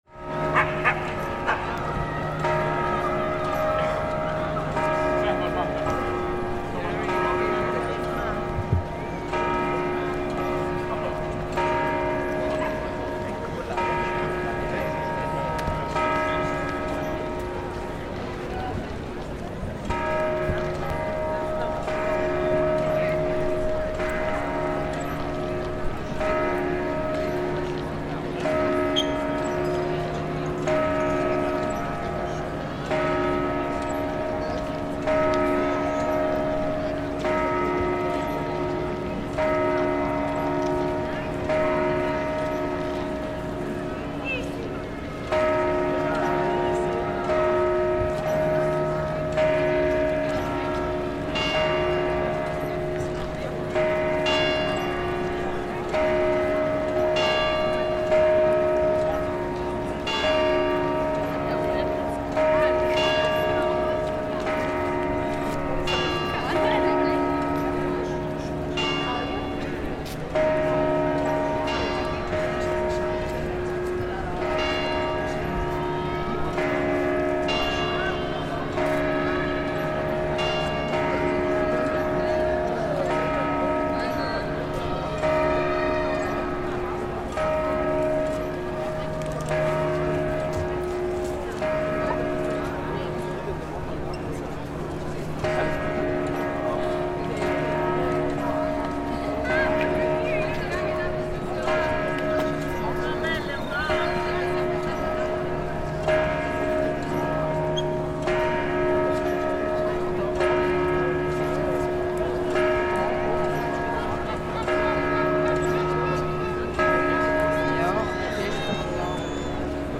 Bells at midday in Venice
In Piazza San Marco, we await the bells at midday, which are heralded by a series of musical chimes coming from the Torre dell'Orologio.
The biggest bell actually doesn't ring at midday, the chimes instead coming from smaller bells inside the huge campanile, so midday sounds a little different from every other time of day in the city.